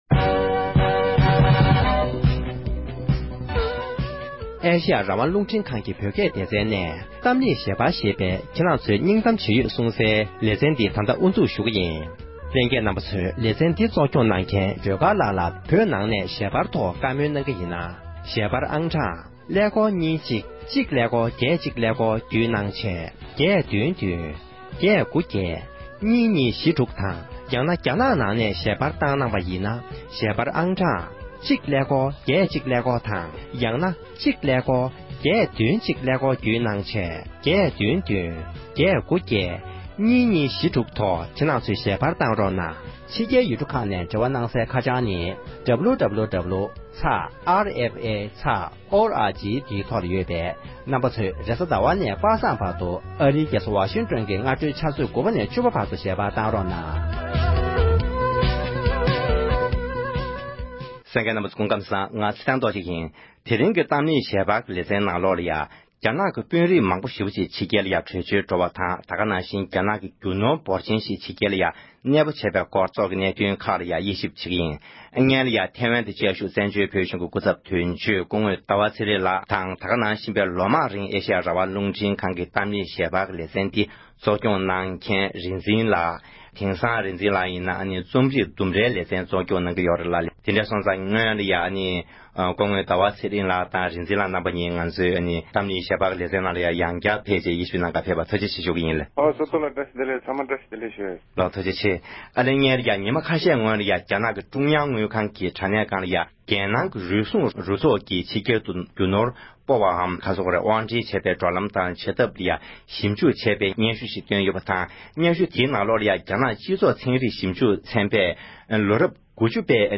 བཀའ་མོལ་ཞུས་པ་ཞིག་ལ་གསན་རོགས་ཞུ